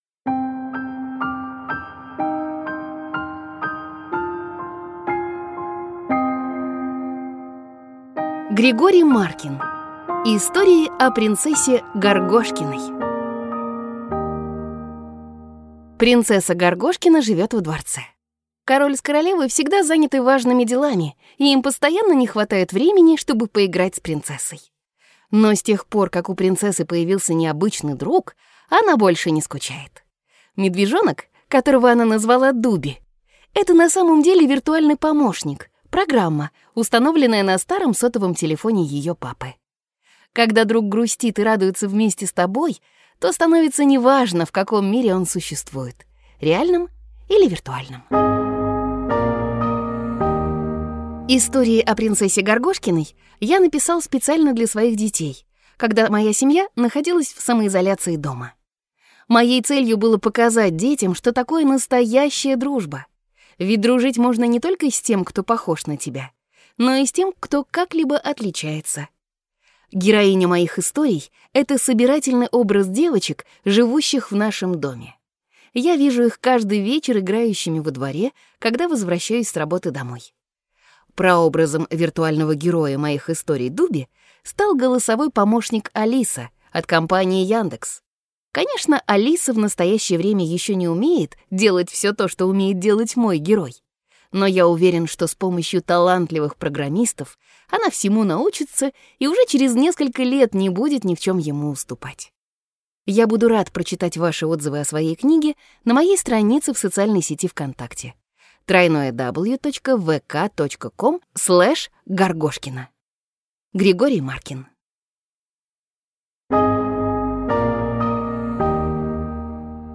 Аудиокнига Истории о принцессе Горгошкиной | Библиотека аудиокниг
Прослушать и бесплатно скачать фрагмент аудиокниги